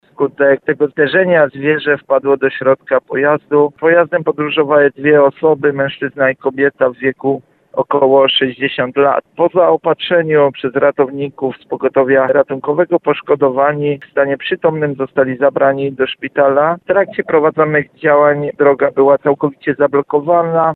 25strazak.mp3